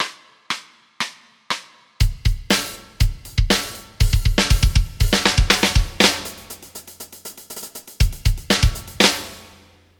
Kick_Snare_HH.mp3